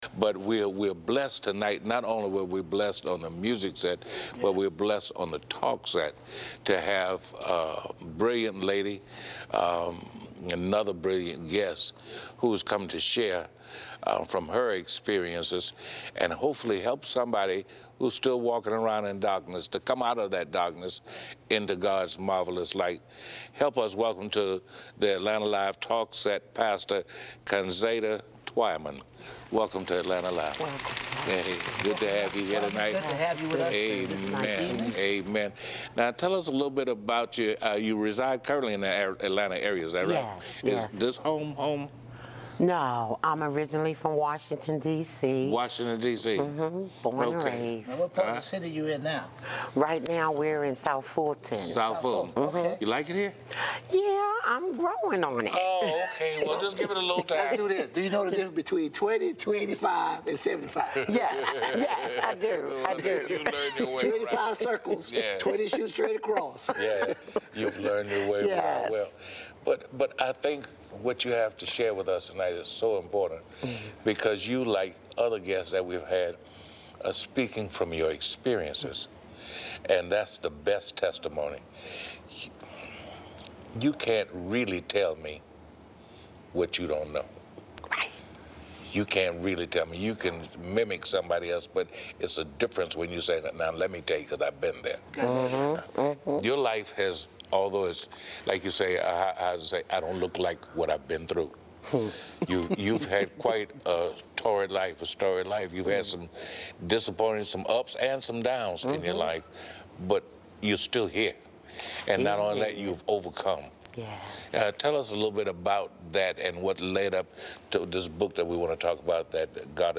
Atlanta-Live-Celebrate-Life.mp3